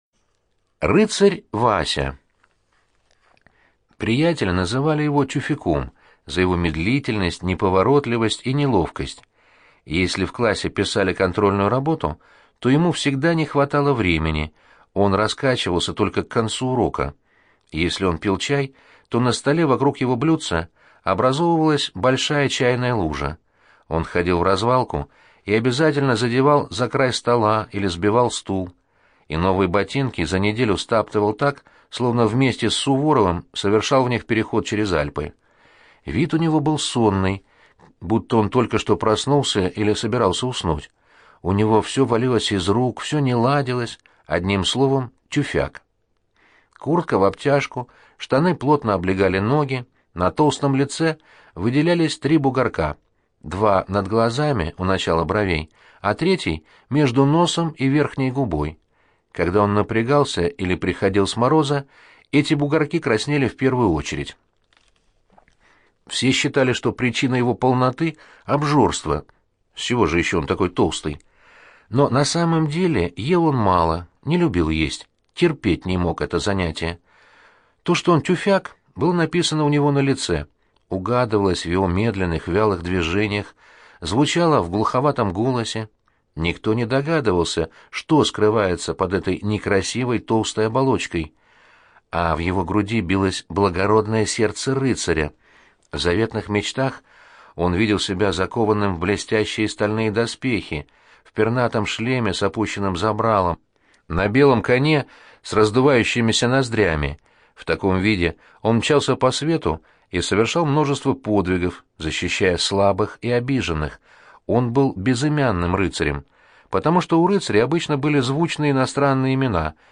Рыцарь Вася - аудио рассказ Яковлева Ю.Я. Рассказ про мальчика, которого в школе называли тюфяком за то, что он был медлительный, неловкий.